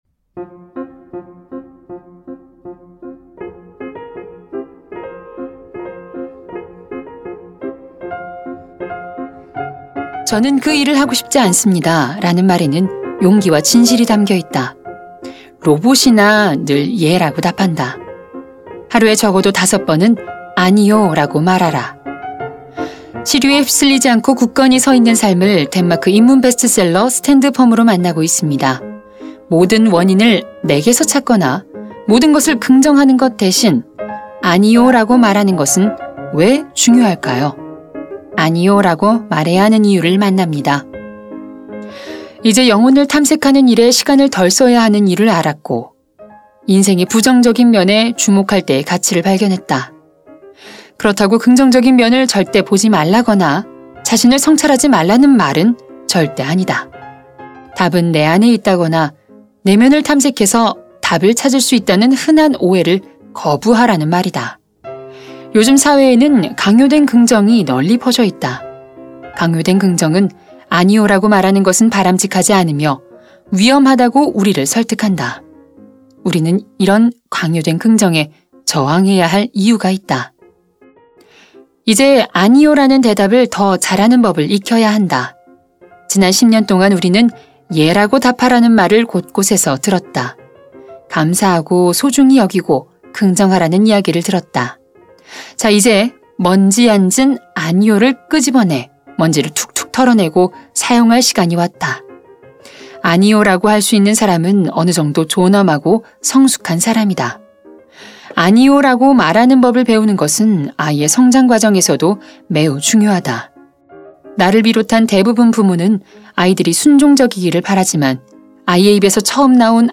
매거진 책 듣는 5분 ㅣ 오디오북